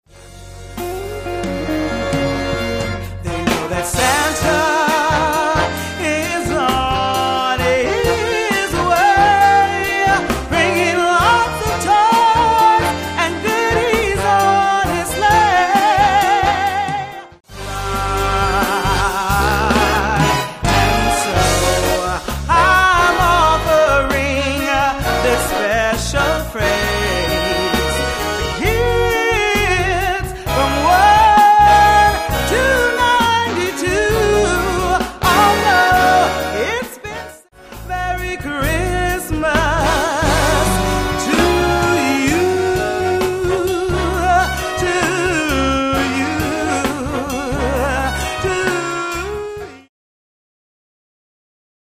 Vocalist with Jazz Ensemble